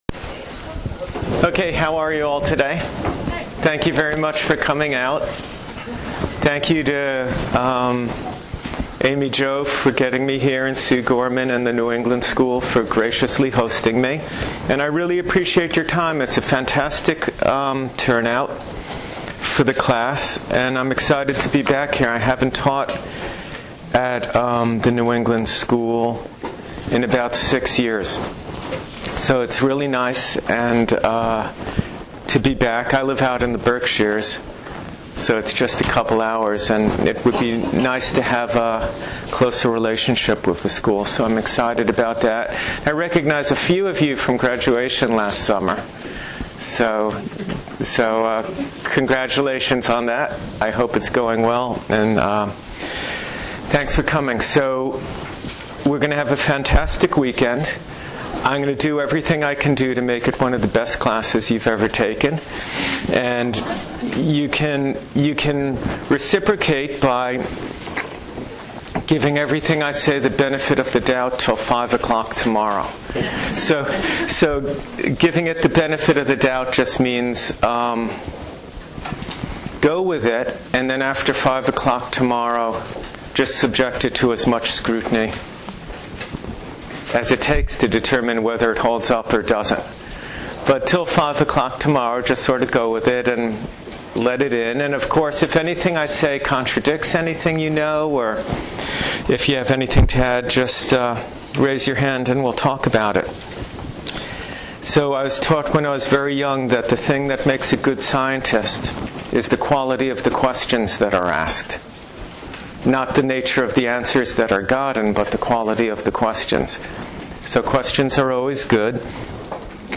Here is the audio to the first part of my lecture at the new England school last weekend. It is the introduction to my class on Five Element constitutional type.